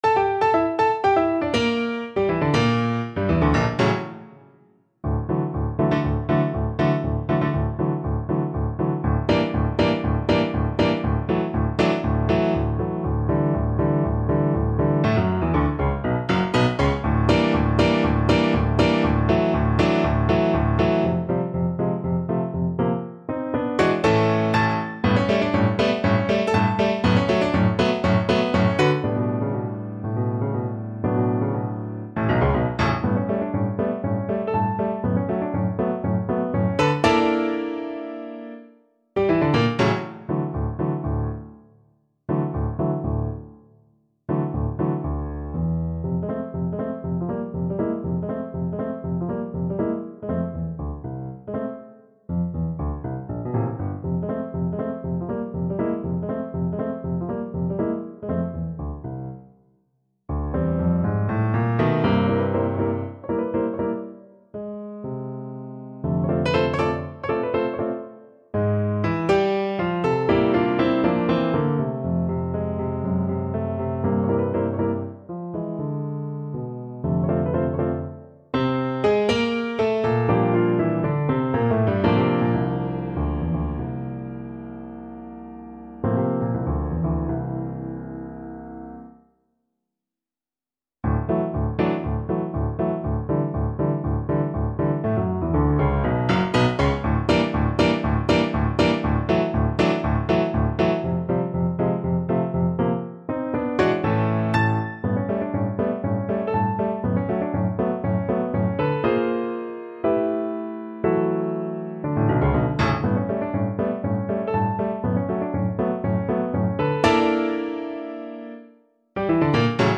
2/4 (View more 2/4 Music)
Allegro giusto (View more music marked Allegro)
Classical (View more Classical Violin Music)